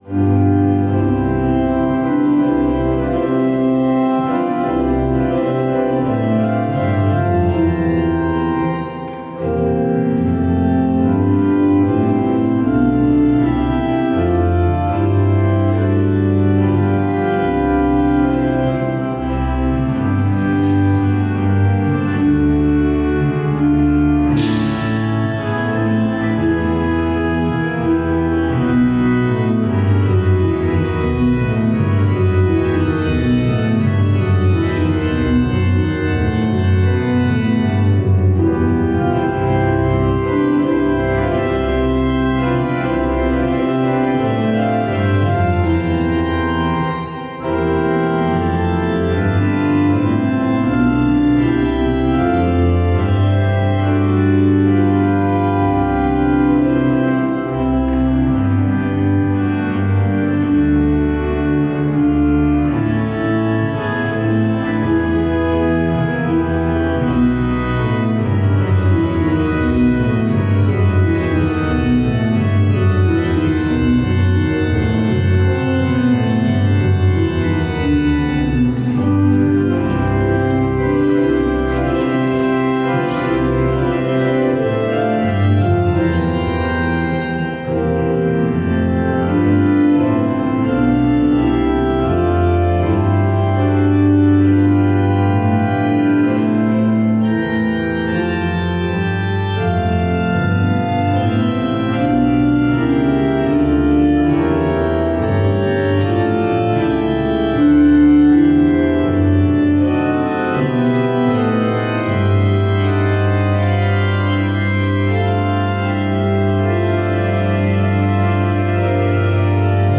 Az Ige testté lett – Budahegyvidéki Református Egyházközség